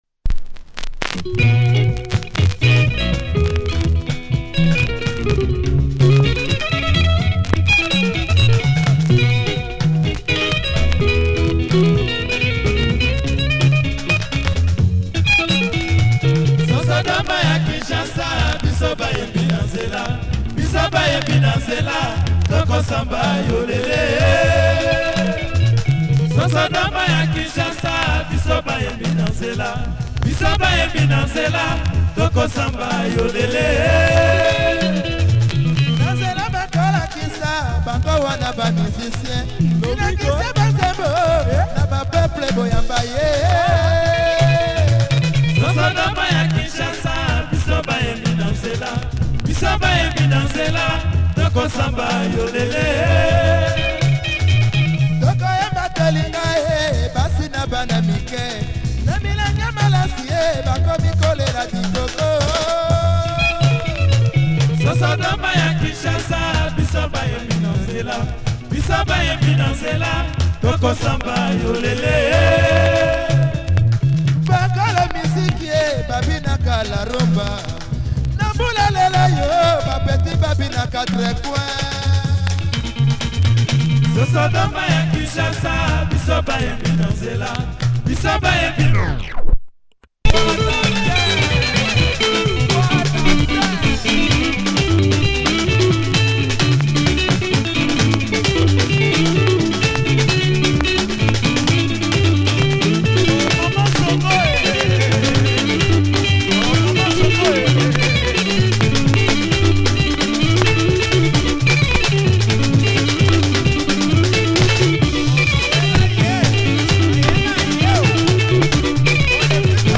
Great gitar here